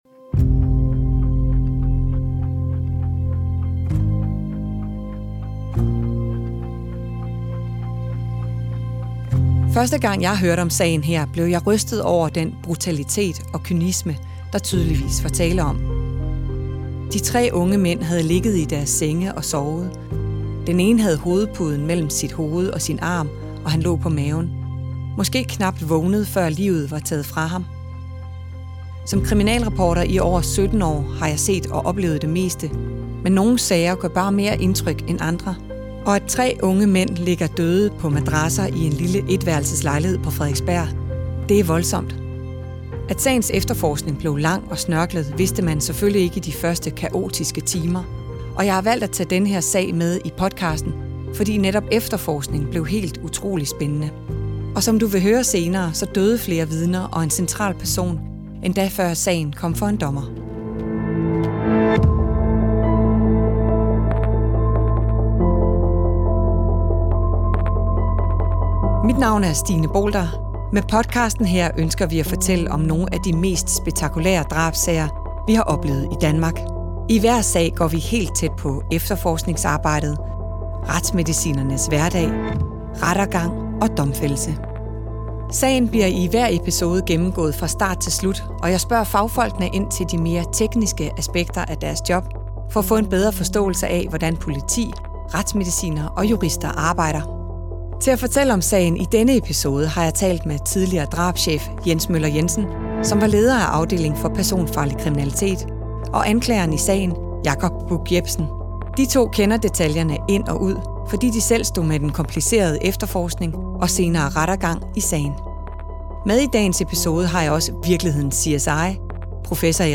Rekonstruktion af nødopkald